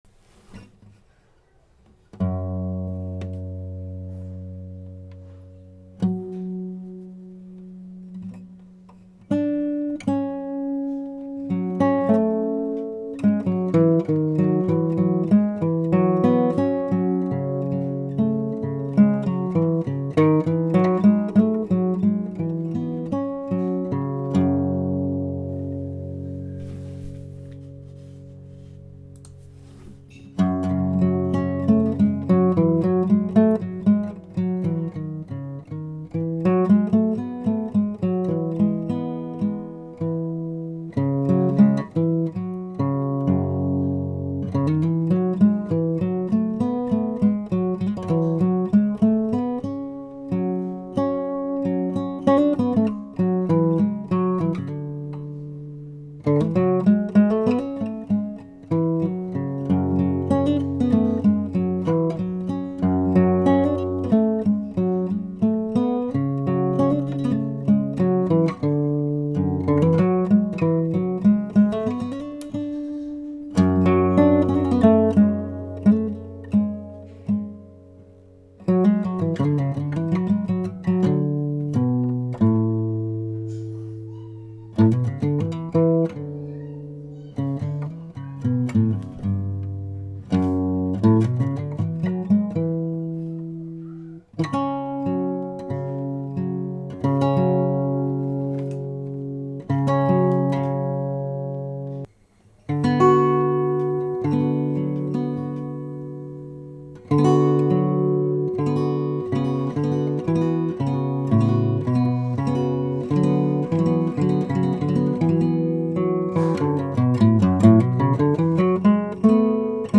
The recordings are all quite amateur, and most are, what I would refer to as, sketches, as opposed to finished and refined pieces of music.
Most are instrumental guitar, some are banjo and a few other instruments that I play with enough skill to record with them.